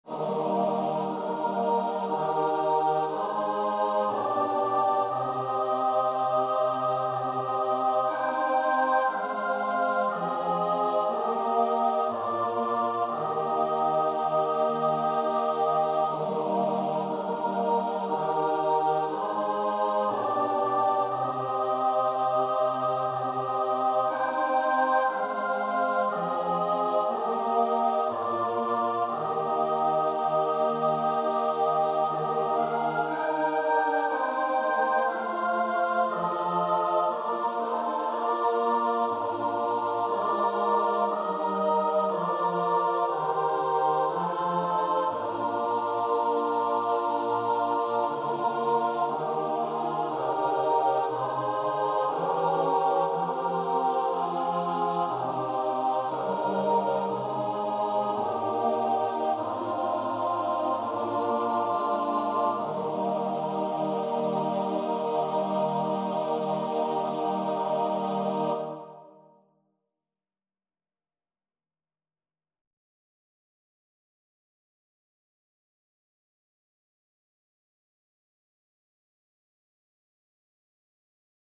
Free Sheet music for Choir (SATB)
Classical (View more Classical Choir Music)